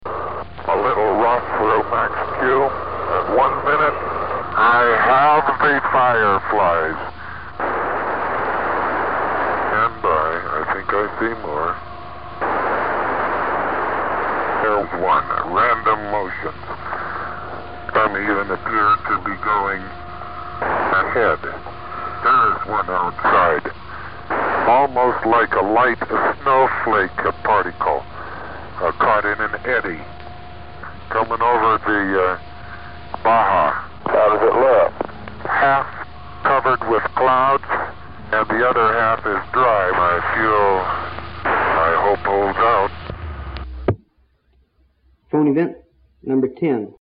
Another astronaut communication involves John Carpenter's observation of some mysterious "fireflies" over Baja California.